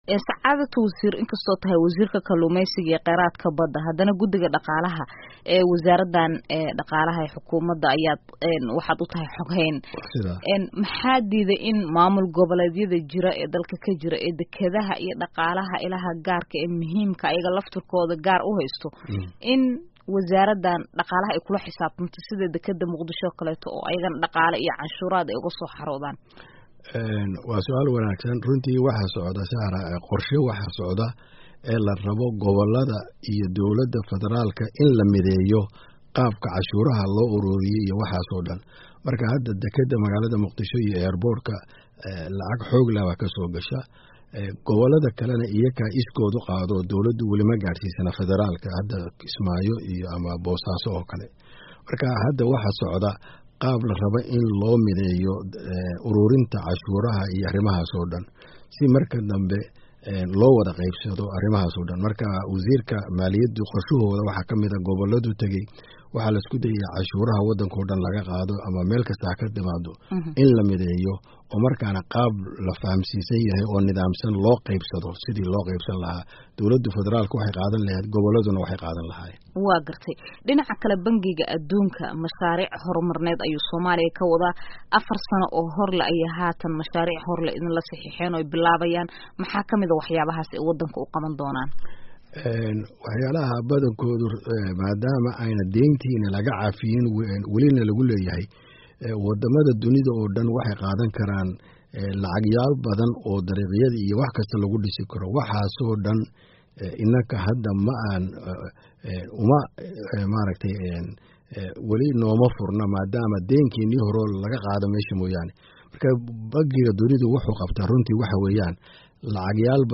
Wareysi: Wasiirka Kalluumeysiga ee Dowladda Soomaaliya